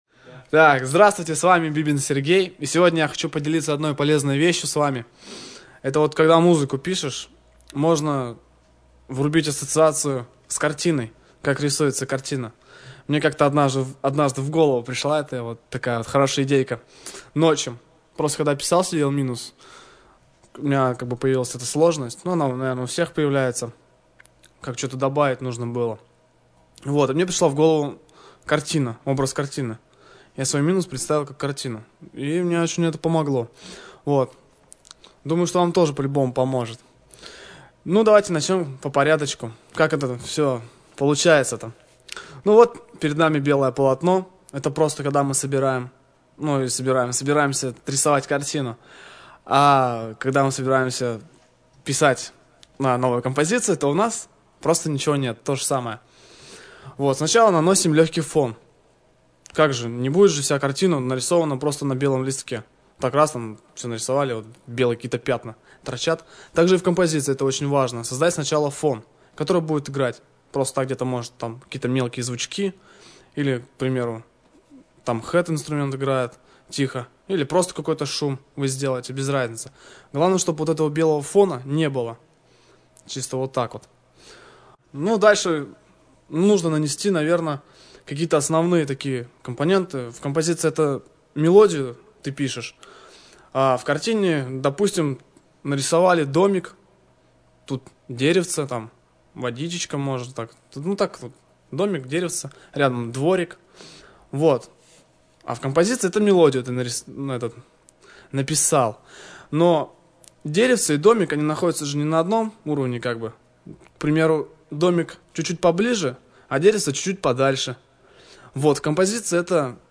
Урок № 2